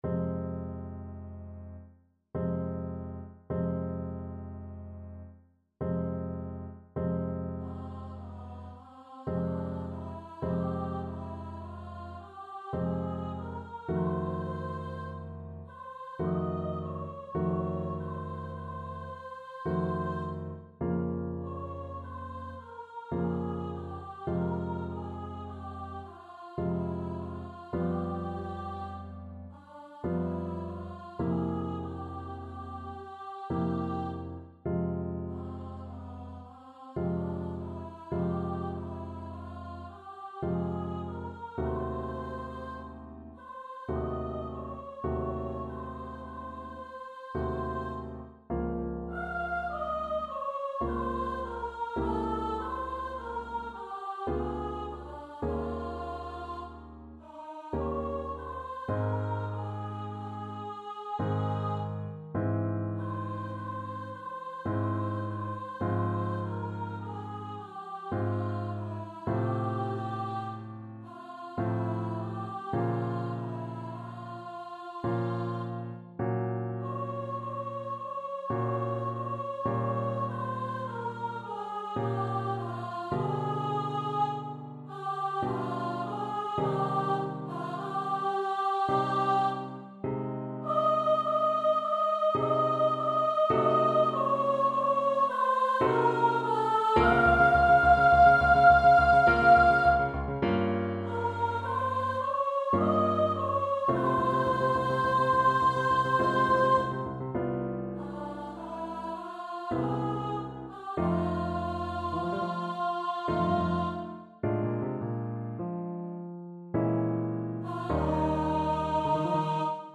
3/4 (View more 3/4 Music)
Andante molto moderato (=66) ~ = 52
Classical (View more Classical Mezzo Soprano Voice Music)